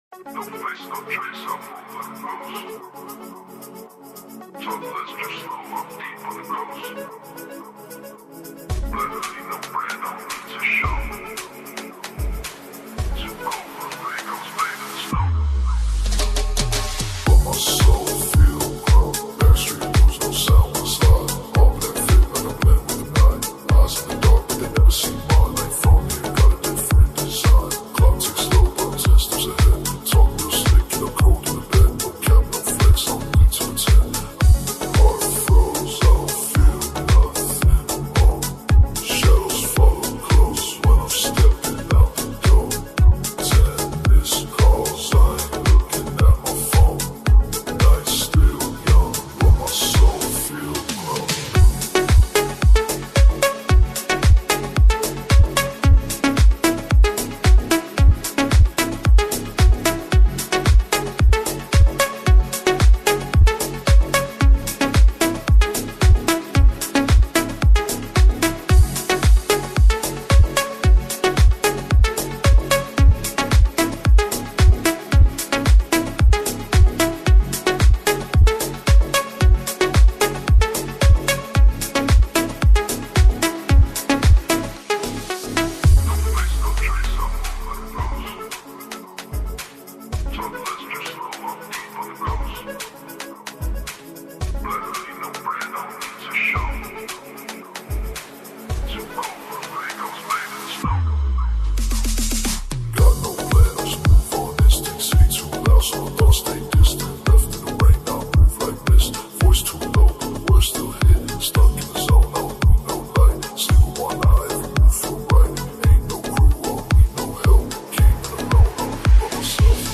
30 Minutes Of Silence 🤐 Sound Effects Free Download